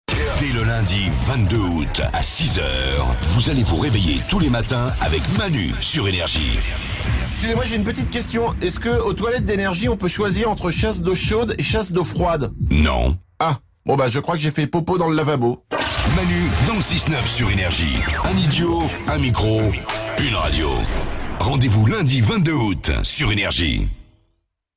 Avec un bon vieux mixage stéréo ^^